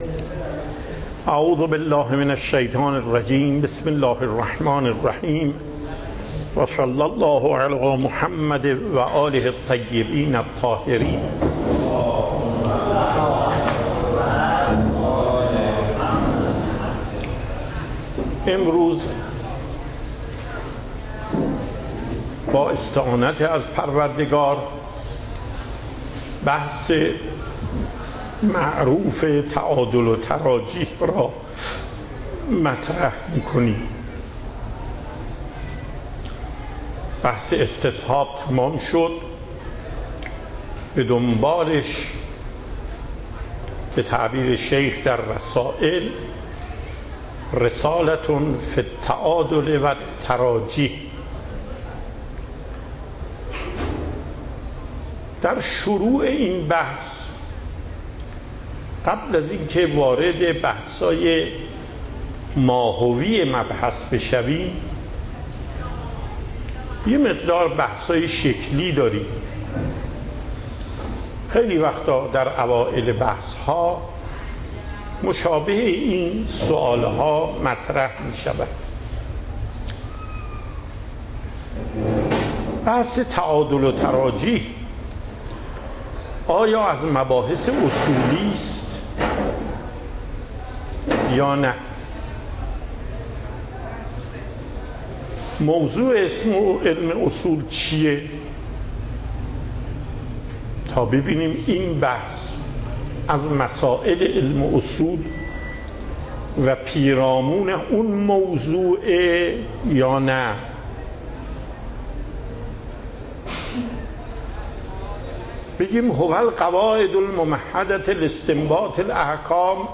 پخش صوت درس: